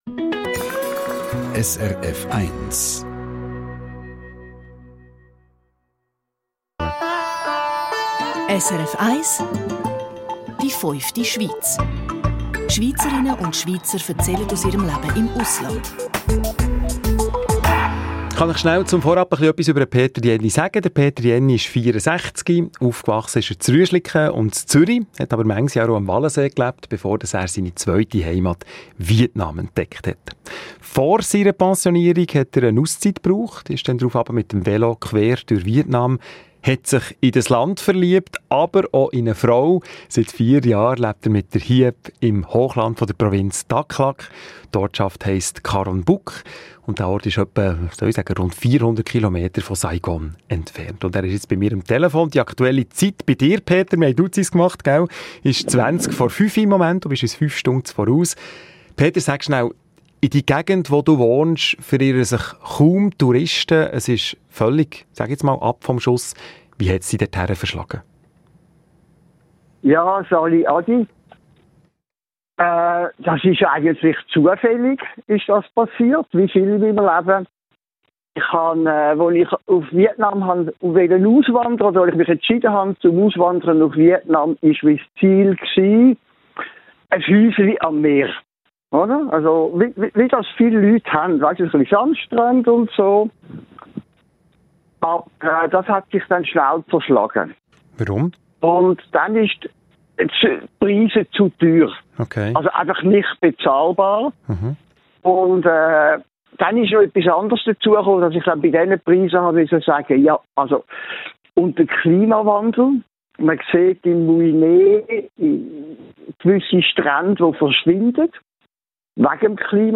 Bericht aus der fünften Schweiz auf SRF1